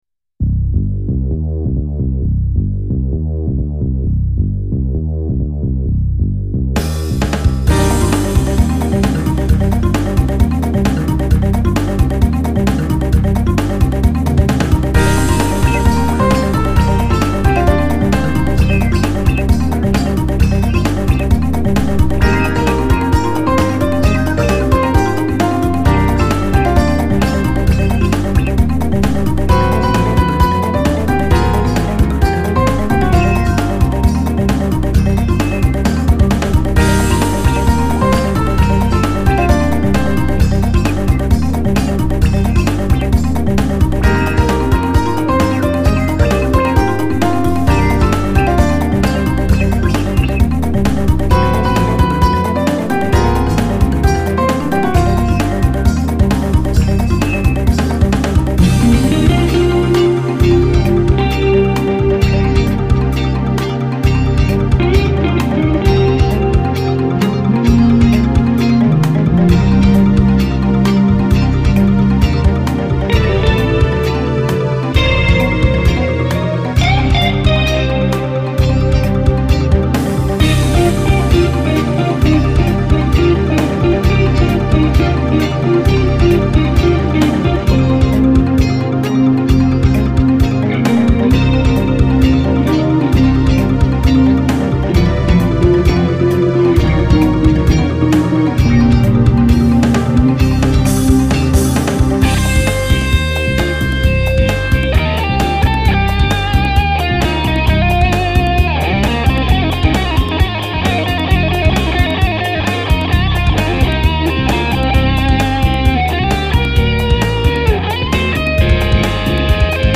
Konzert- und E-Gitarre